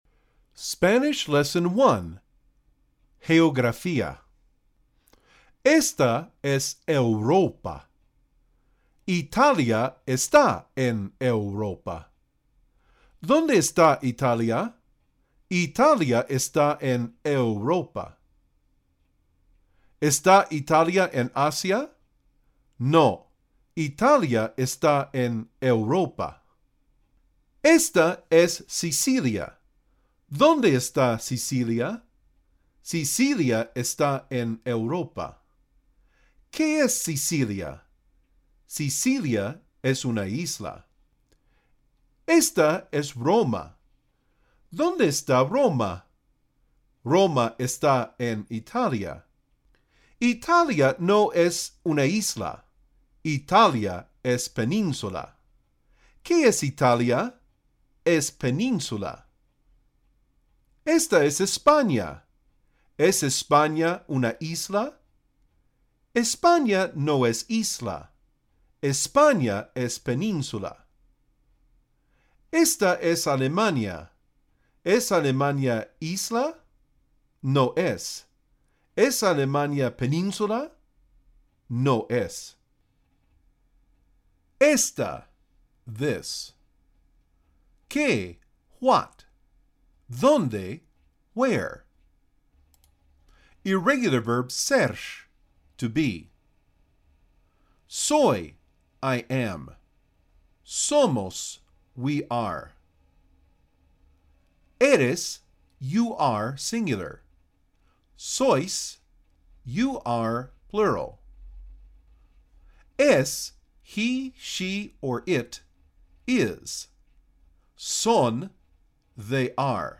Spanish Lesson 1